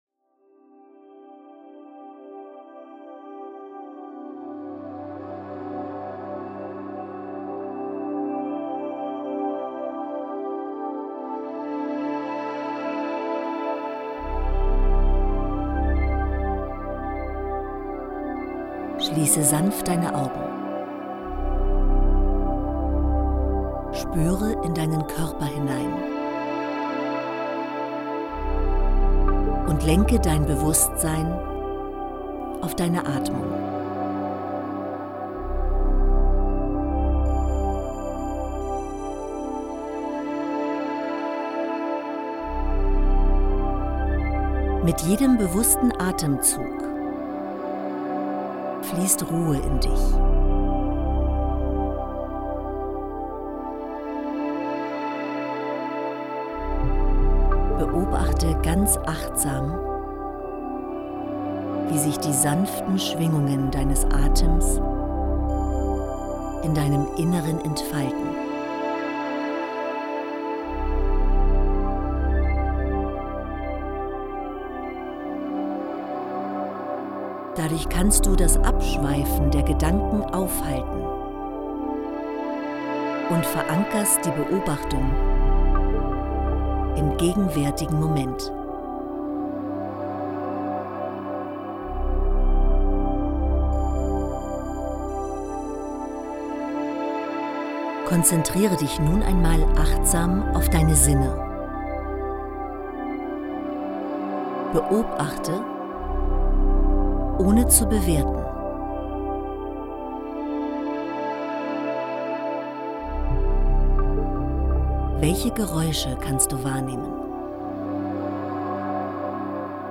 Genießen Sie besondere Wohlfühl-Momente mit  Musik zum Entspannen und einer geführten Wellness-Meditation.
Titel 3 – Meditation Zeit für dich – 9 min